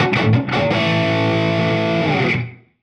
Index of /musicradar/80s-heat-samples/85bpm
AM_HeroGuitar_85-A01.wav